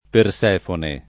perS$fone] pers. f. mit. (= Proserpina) — es. con acc. scr.: Torna Persèfone da gli occhi ceruli [t1rna perS$fone da l’l’ 0kki ©$ruli] (Carducci) — raro, alla greca, Persefóne (D’Annunzio) o più esattam. Persefòne (Pavese)